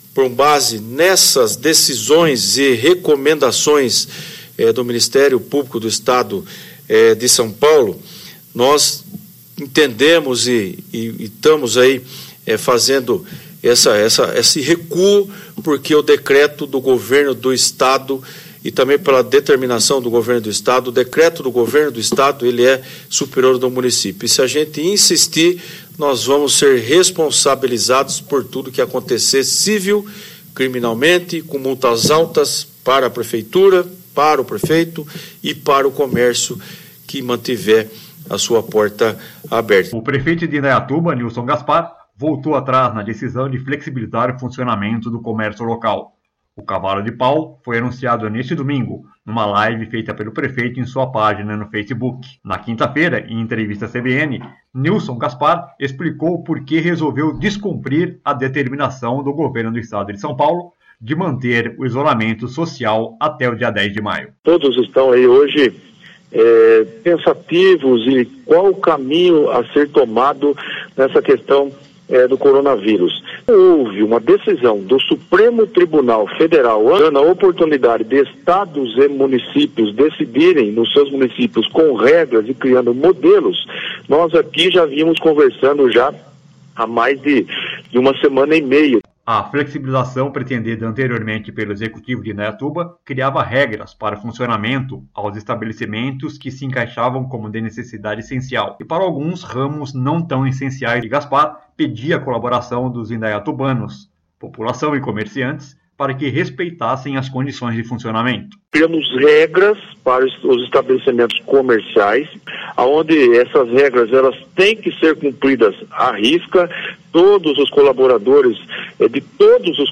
Na quinta-feira, em entrevista à CBN, Nilson Gaspar explicou porque resolveu descumprir a determinação do Governo do Estado de São Paulo de manter o isolamento social até o dia 10 de maio.